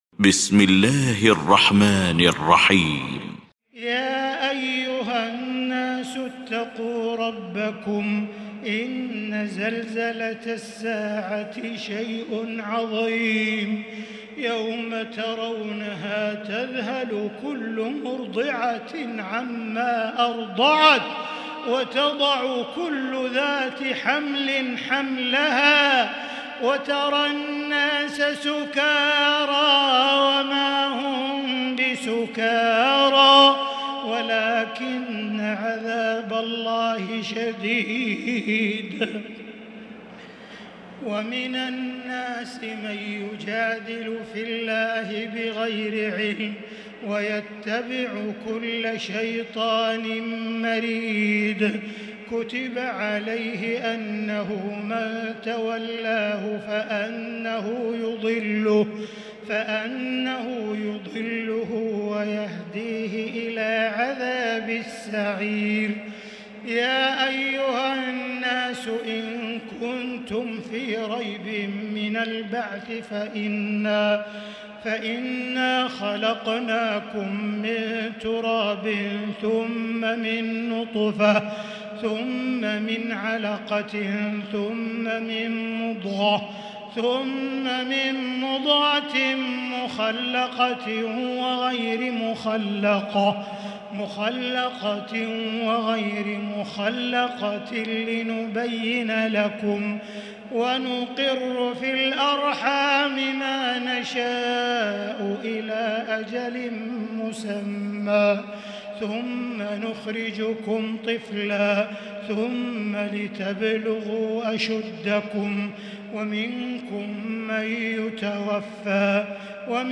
المكان: المسجد الحرام الشيخ: معالي الشيخ أ.د. بندر بليلة معالي الشيخ أ.د. بندر بليلة سعود الشريم الحج